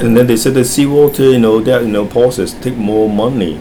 S2 = Hong Kong male Context: S2 is talking about human wastage of water, and how they recycle their rainwater in Singapore.
The main issue is the absence of [r] in the word.